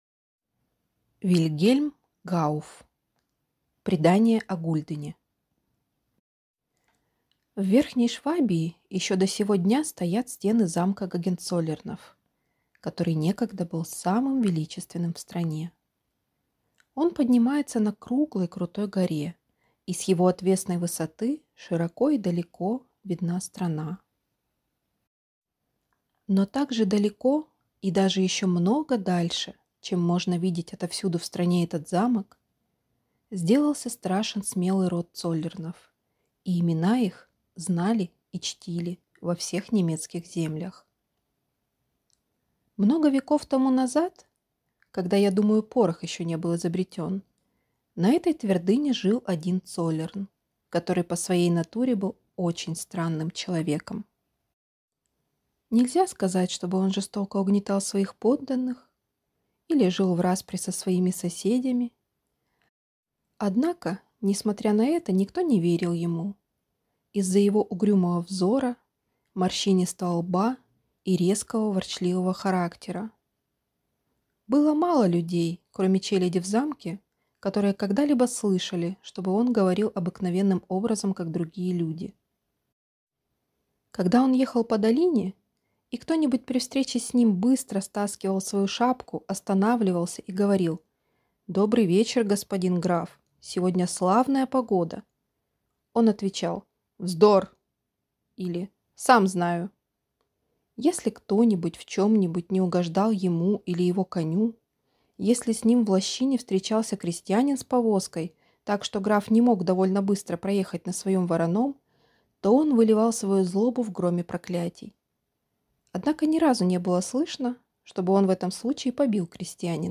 Аудиокнига Предание о гульдене | Библиотека аудиокниг